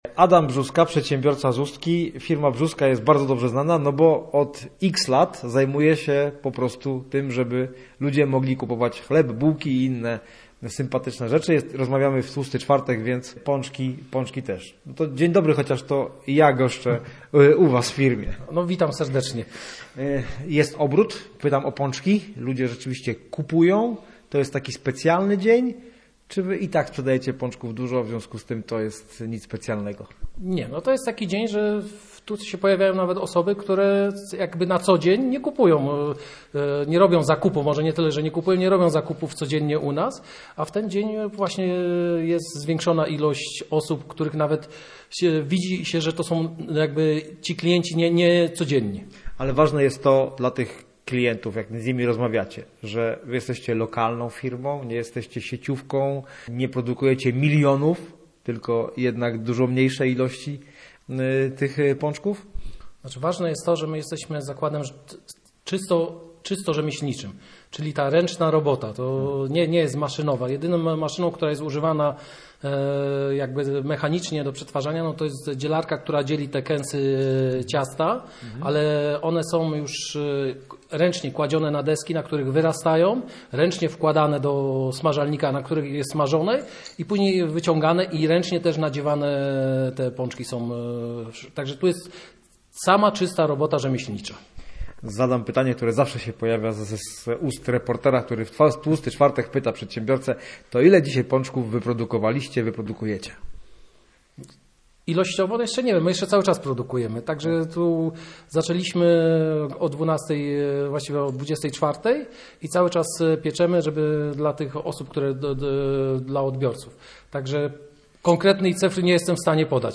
W tłusty czwartek piekarnia w Ustce przygotowała dziesięć tysięcy pączków. Posłuchaj rozmowy